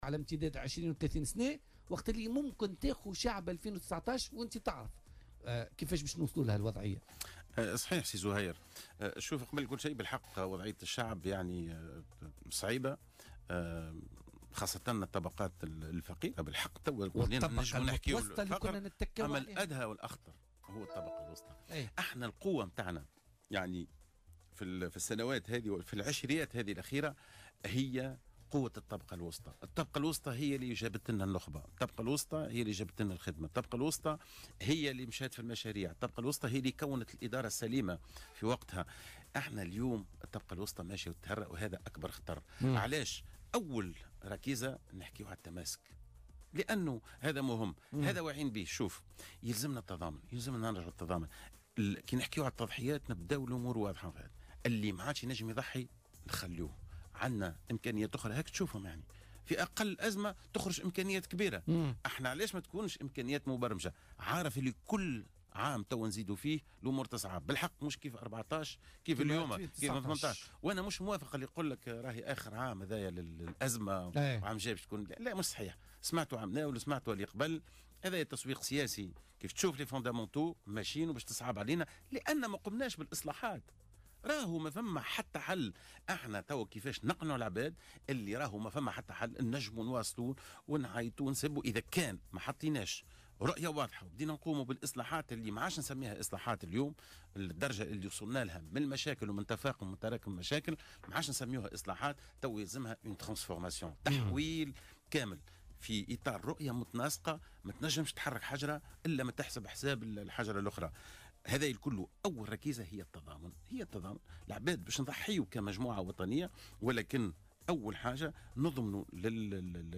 أكد رئيس حزب البديل التونسي مهدي جمعة ضيف بولتيكا اليوم الإثنين 22 جانفي 2018 أن الأمور في تونس تتجه نجو التصعيد وان الأزمة ستشتد أكثر بمرور الوقت حسب قوله.